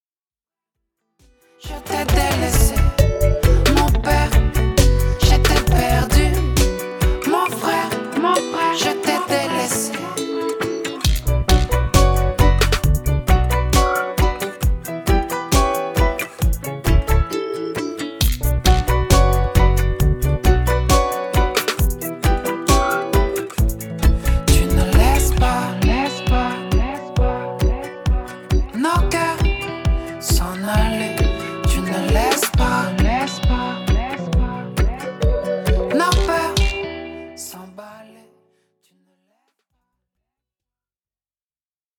sonorités house
Le reggae reste néanmoins le fil conducteur de cet EP.